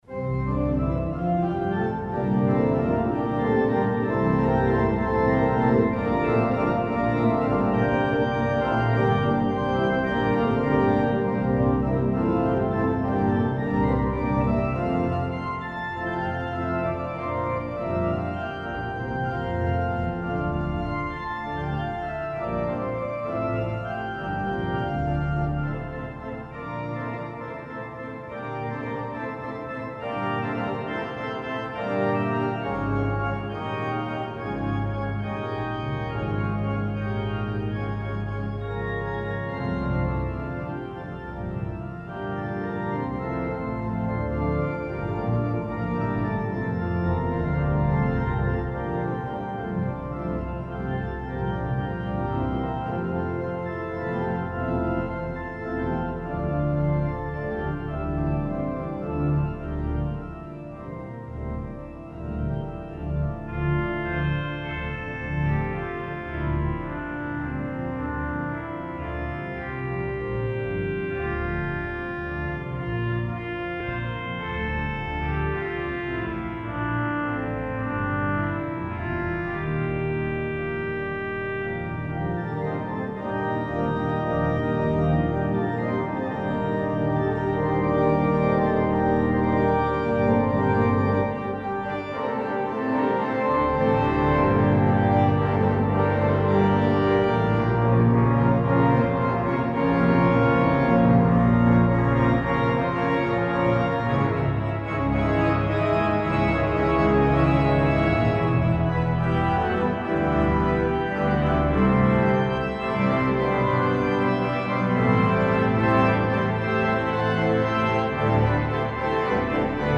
The interview includes samples of hymns, anthems, and organ improvisations from the Cathedral of St Philip.
an improvisation